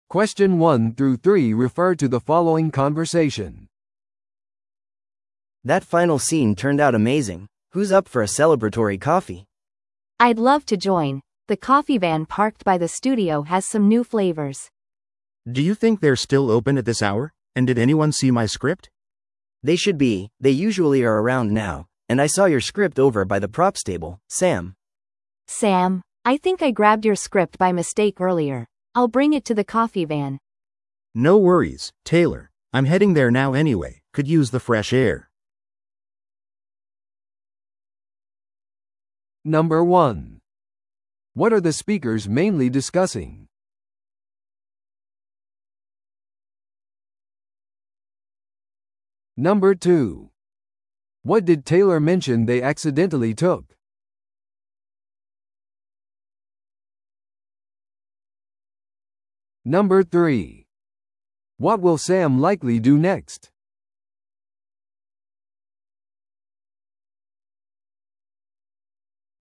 TOEICⓇ対策 Part 3｜映画の撮影終了後の雑談 – 音声付き No.208